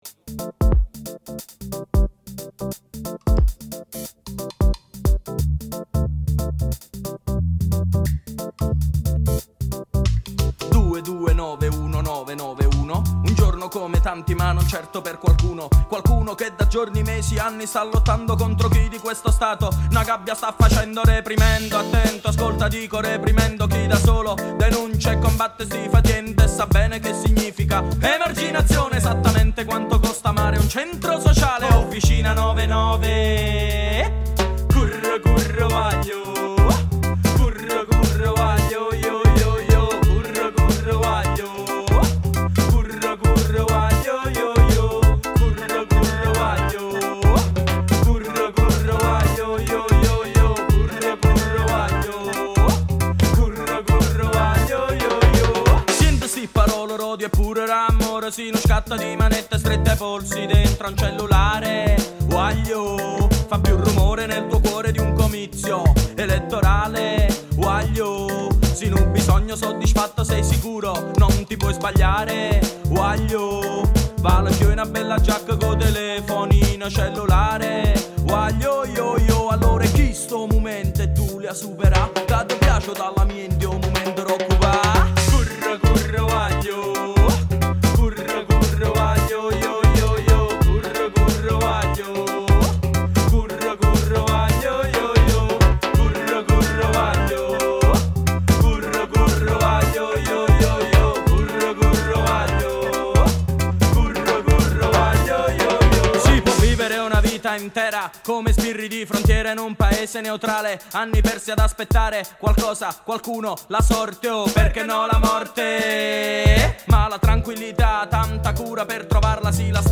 🔥 99 Posse: Parole, Ritmi e Resistenza dal Rototom Sunsplash
Artista-a-la-Vista-Intervista-99-Posse.mp3